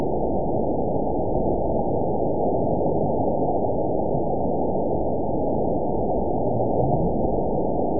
event 921005 date 04/23/24 time 21:46:09 GMT (1 year, 1 month ago) score 9.00 location TSS-AB02 detected by nrw target species NRW annotations +NRW Spectrogram: Frequency (kHz) vs. Time (s) audio not available .wav